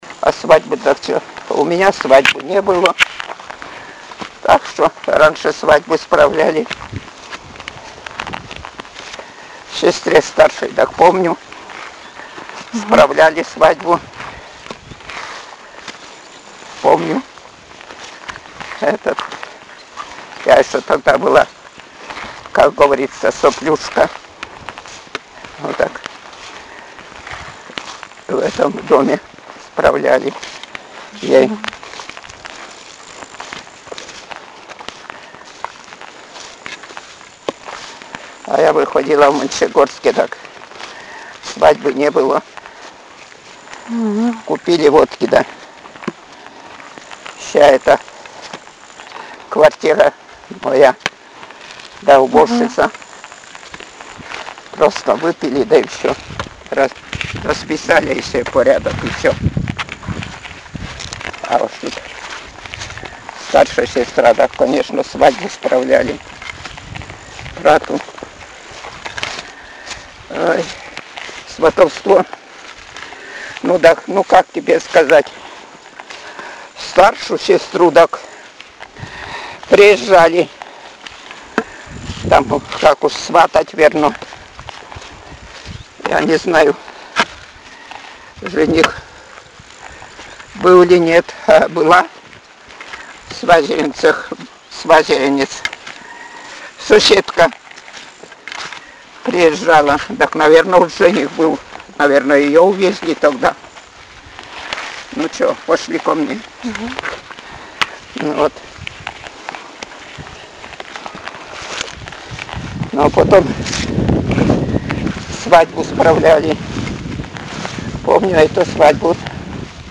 Пол информанта: Жен.
Место записи: Плах.
Аудио- или видеозапись беседы: